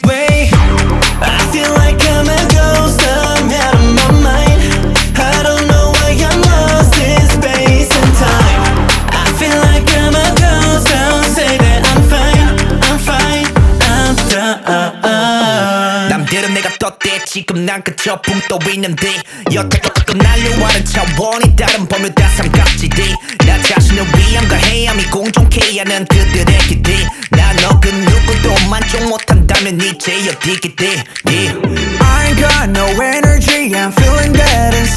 K-Pop Pop
2025-08-22 Жанр: Поп музыка Длительность